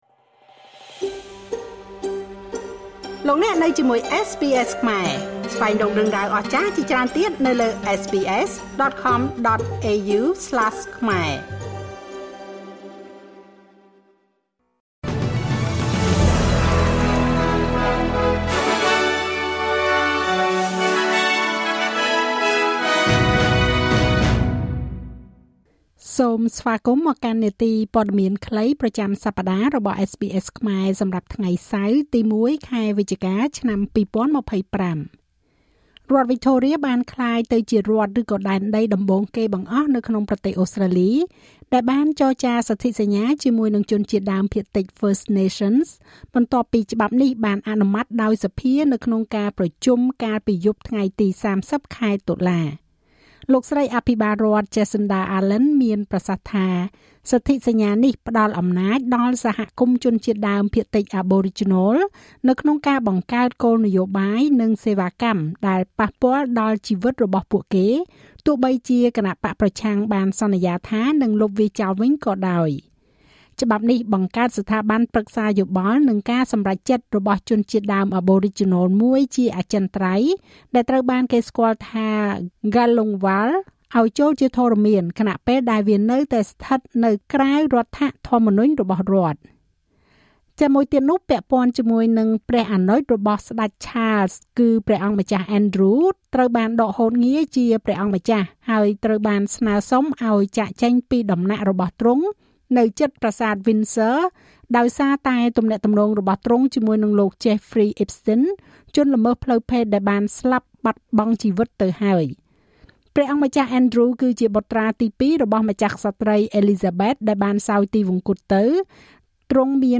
នាទីព័ត៌មានខ្លីប្រចាំសប្តាហ៍របស់SBSខ្មែរ សម្រាប់ថ្ងៃសៅរ៍ ទី១ ខែវិច្ឆិកា ឆ្នាំ២០២៥